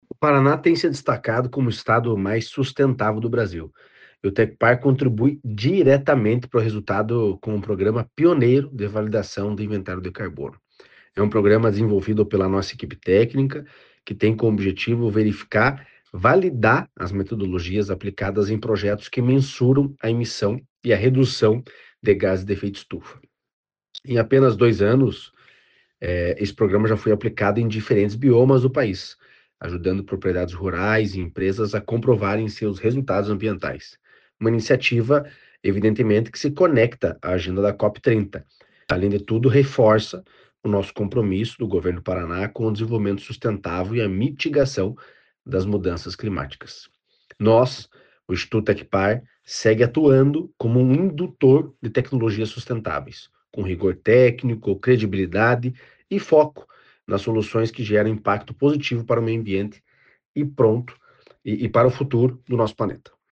Sonora do diretor-presidente do Tecpar, Eduardo Marafon, sobre o programa para validação de inventários de carbono